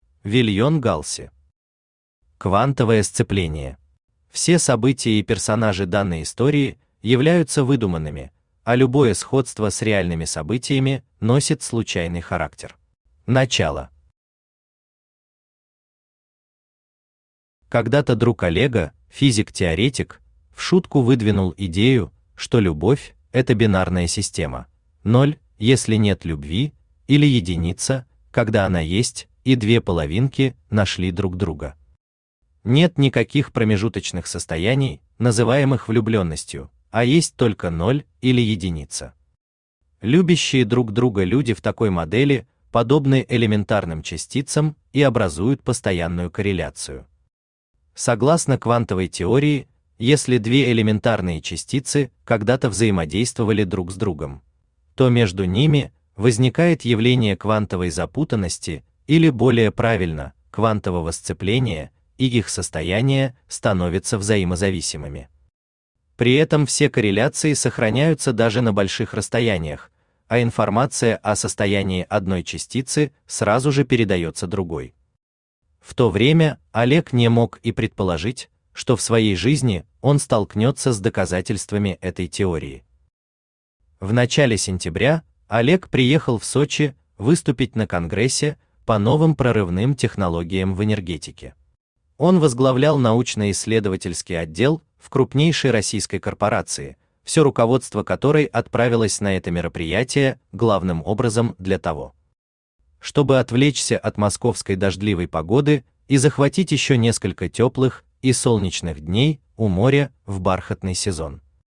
Аудиокнига Квантовое сцепление | Библиотека аудиокниг
Aудиокнига Квантовое сцепление Автор Вильен Галси Читает аудиокнигу Авточтец ЛитРес.